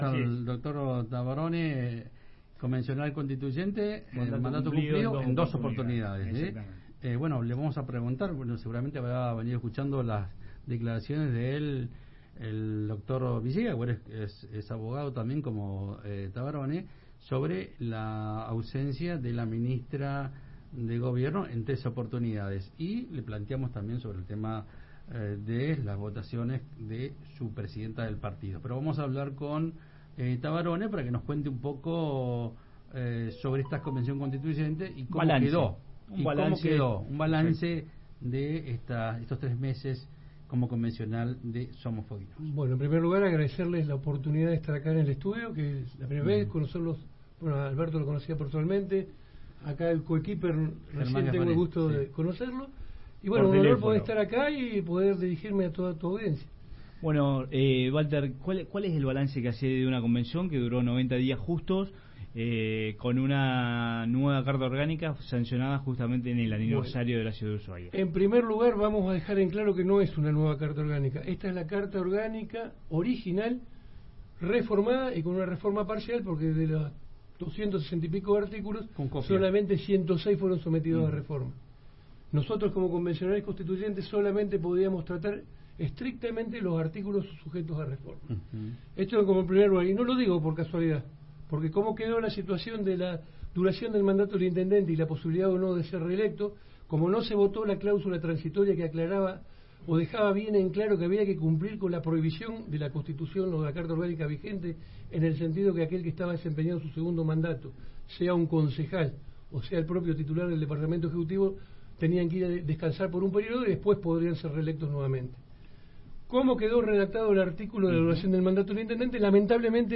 Río Grande.- El ex convencional constituyente Valter Tavarone visitó los estudios de Radio Universidad 93.5 y Diario Provincia 23 para hacer un balance de los tres meses de trabajo.